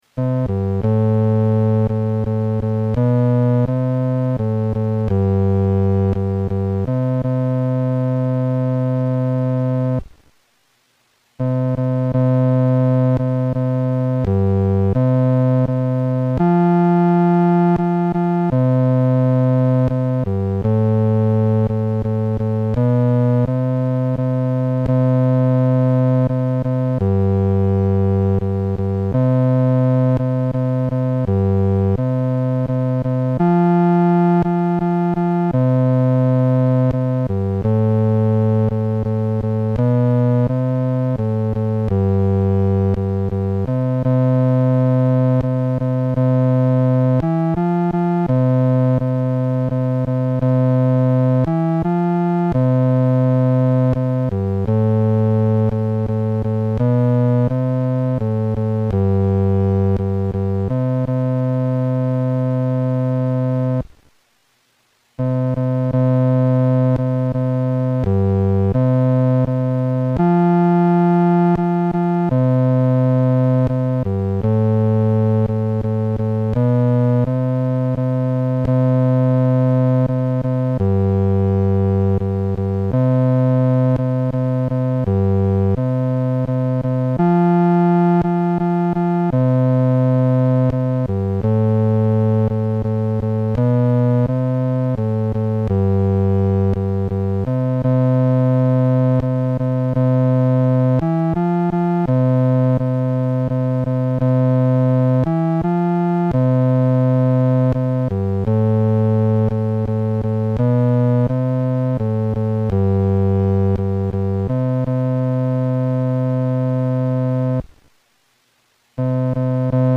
伴奏
男低
曲调欢快、活泼，好唱易记，既适合成人唱，又适合年轻人唱，还特别适合儿童表演唱。